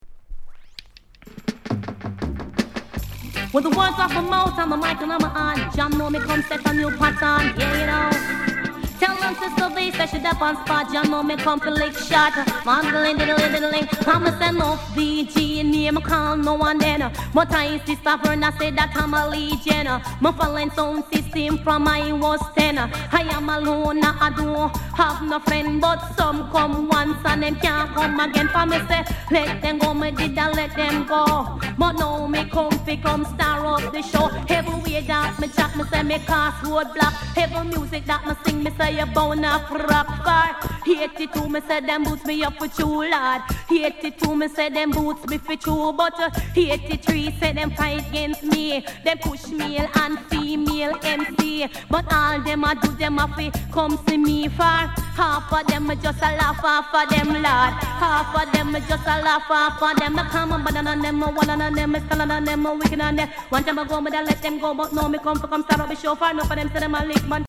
BAD FEMALE DEE JAY